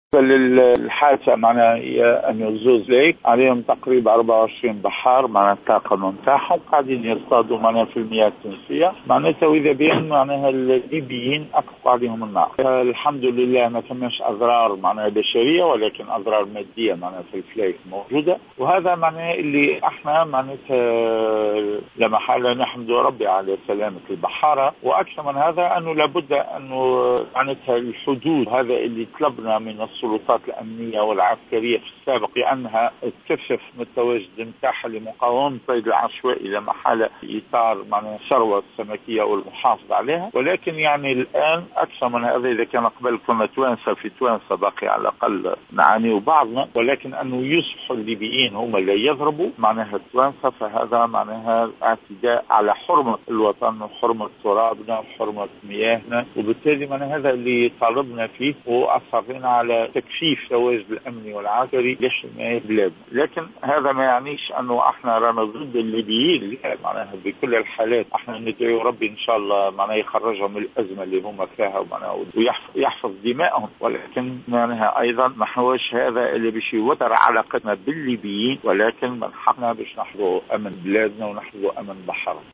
في تصريح للجوهرة أف أم اليوم الخميس تعرض مركبي صيد على متنهما 24 بحارا تونسيا إلى إطلاق نار من طرف مجموعة من الليبيين عندما كانوا بصدد الصيد في المياه الإقليمية التونسية.